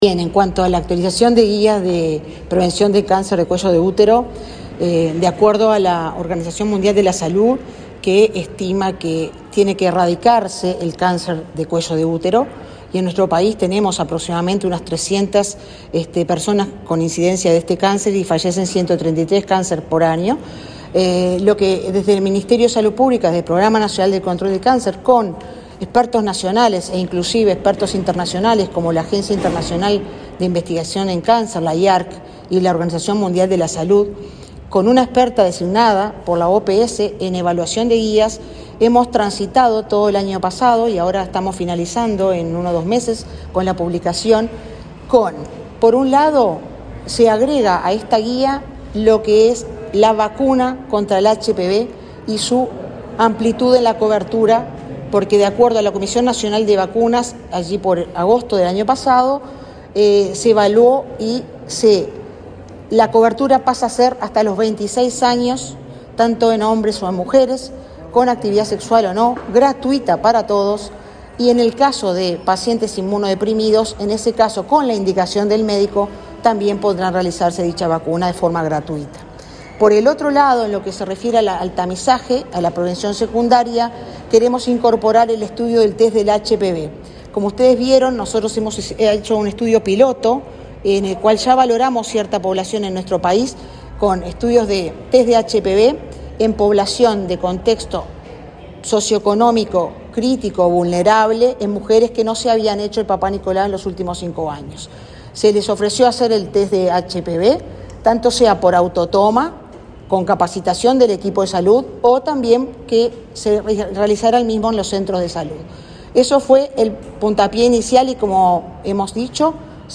Declaraciones de Marisa Fazzino, directora del Programa Nacional de Control de Cáncer
Declaraciones de Marisa Fazzino, directora del Programa Nacional de Control de Cáncer 02/02/2023 Compartir Facebook X Copiar enlace WhatsApp LinkedIn La directora del Programa Nacional de Control de Cáncer, Marisa Fazzino, dialogó con la prensa luego de participar en el acto realizado en el MSP con motivo del Día Mundial de Lucha Contra el Cáncer.